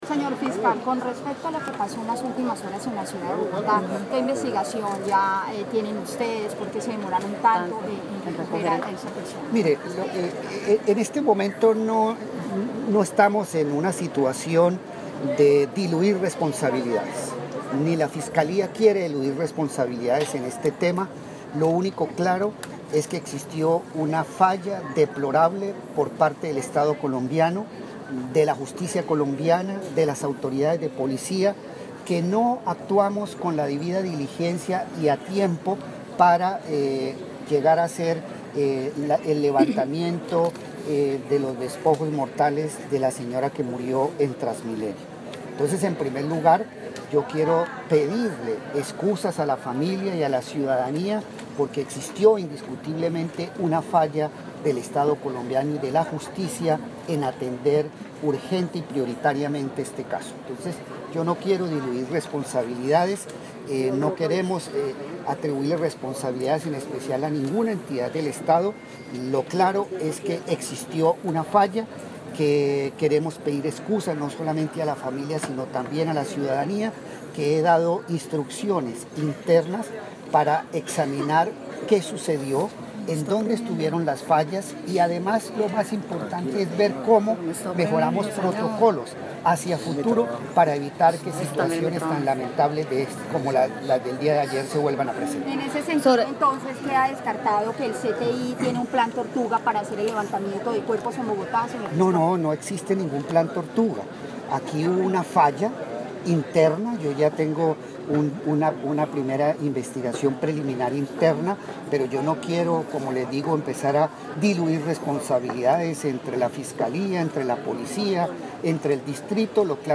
Las declaraciones se produjeron en el marco de la presentación del proyecto de construcción del búnker de la Fiscalía en la ciudad de Cúcuta.
Declaraciones Eduardo Montealegre Lynett, Fiscal General de la Nación
Lugar: Cúcuta (Norte de Santander)